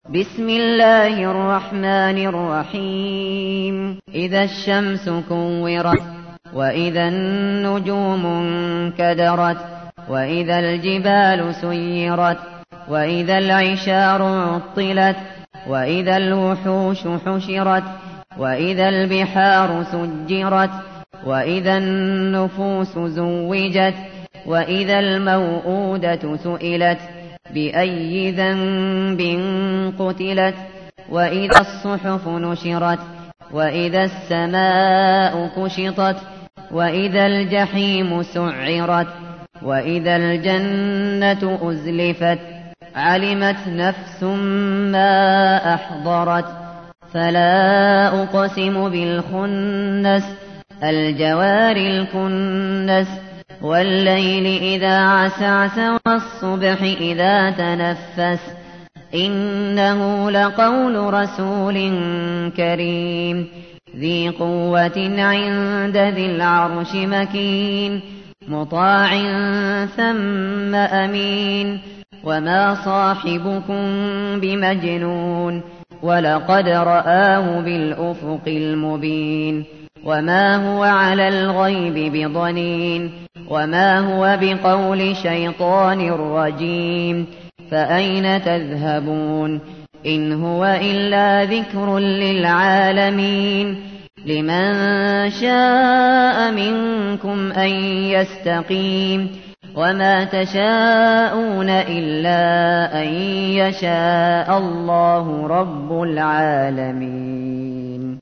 تحميل : 81. سورة التكوير / القارئ الشاطري / القرآن الكريم / موقع يا حسين